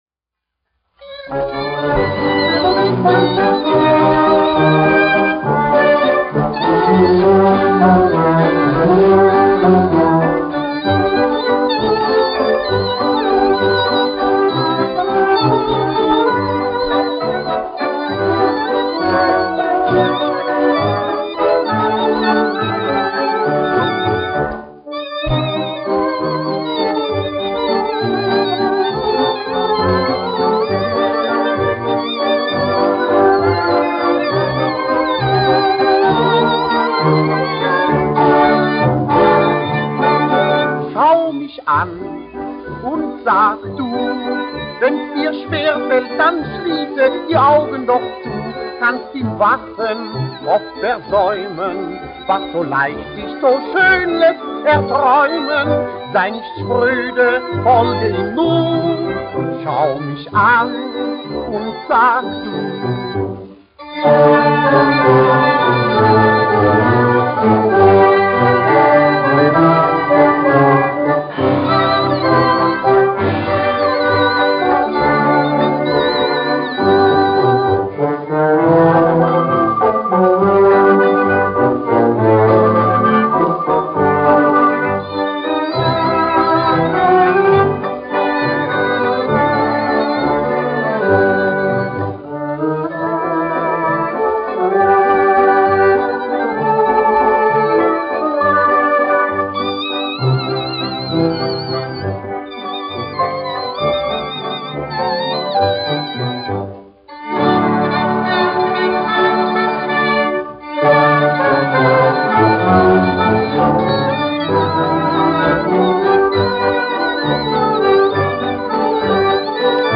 Stimmungswalzer
1 skpl. : analogs, 78 apgr/min, mono ; 25 cm
Populārā mūzika
Valši
Skaņuplate